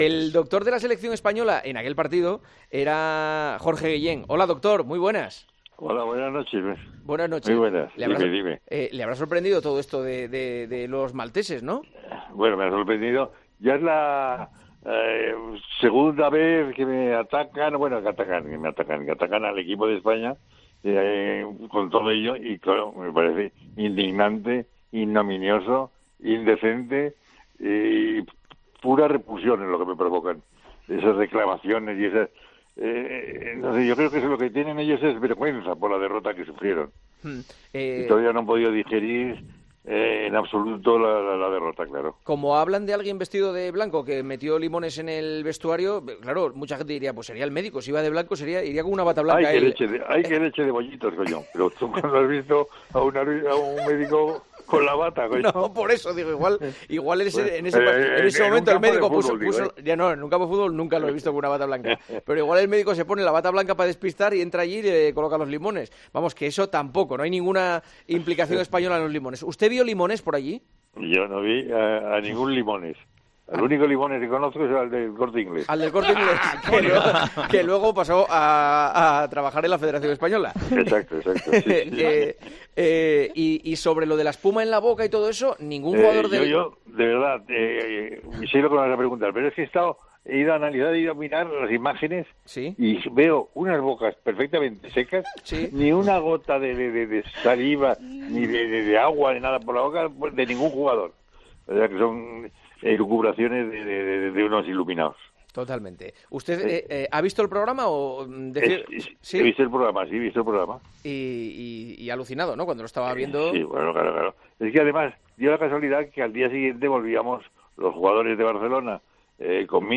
Entrevista en El Partidazo de COPE